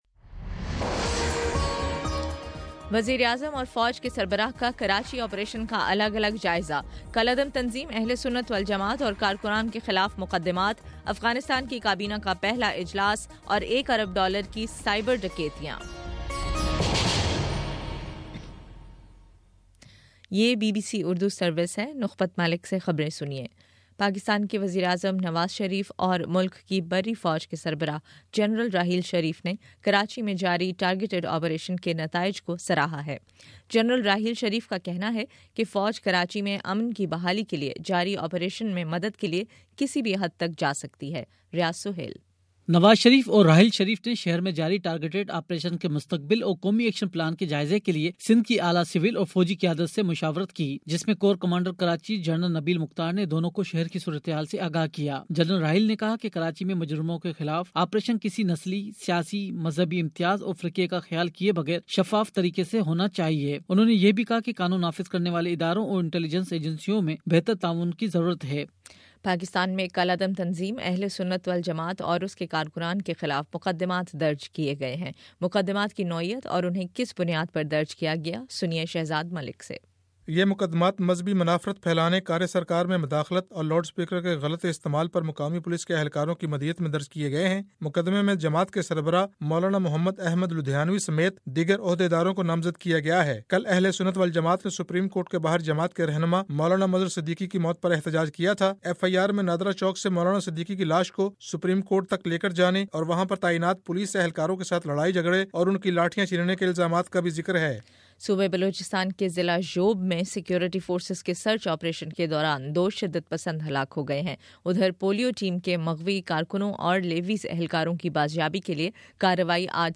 فروری 16: شام چھ بجے کا نیوز بُلیٹن